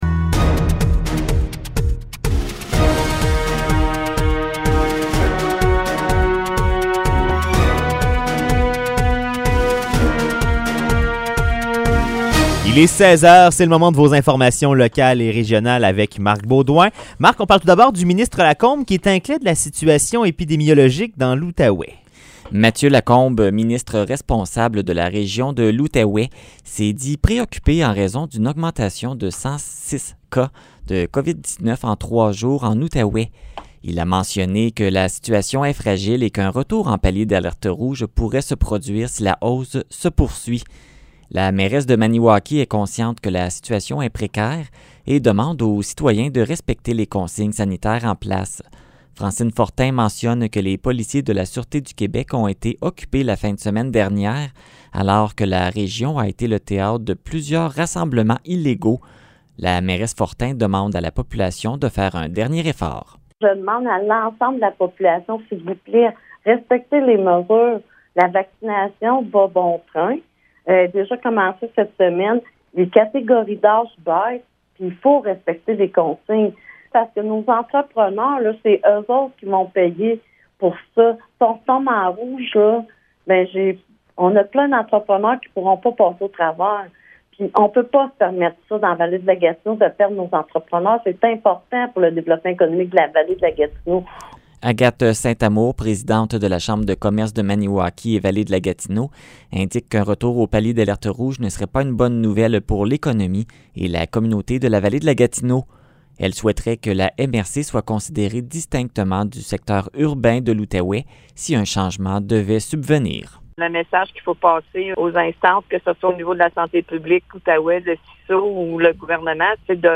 Nouvelles locales - 16 mars 2021 - 16 h